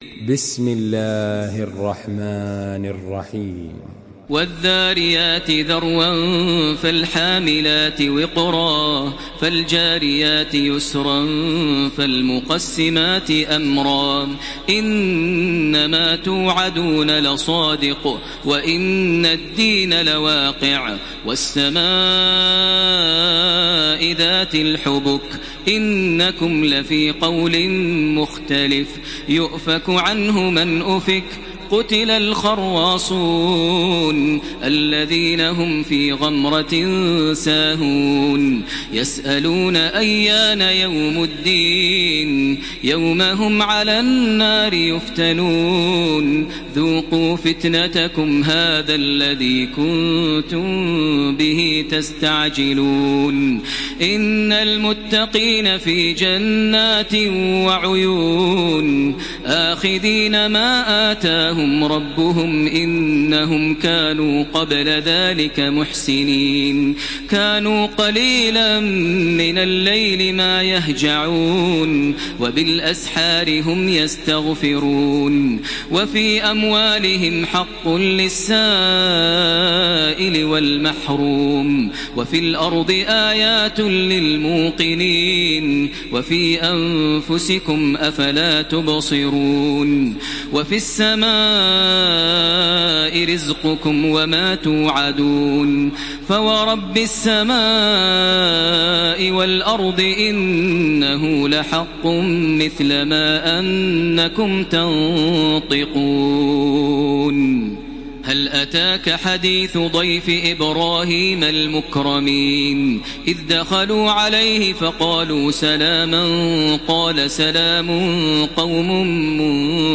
سورة الذاريات MP3 بصوت تراويح الحرم المكي 1433 برواية حفص
مرتل